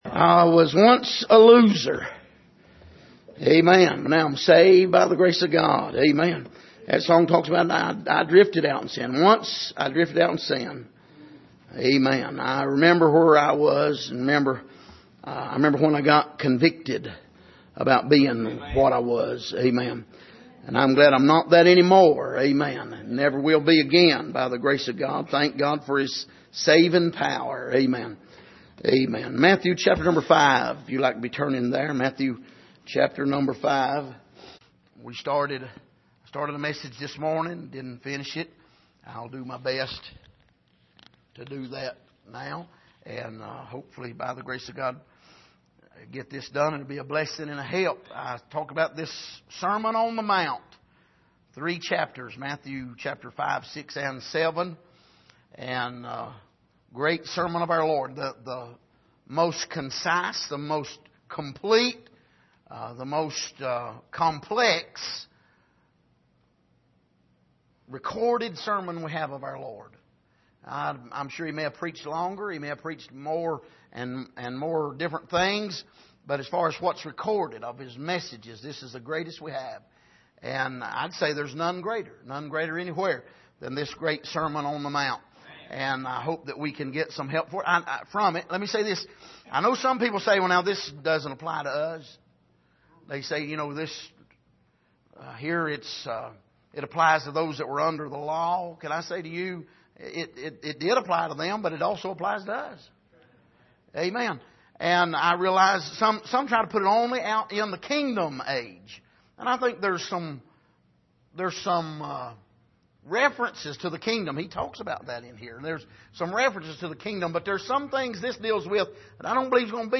Passage: Matthew 5:1-5 Service: Sunday Evening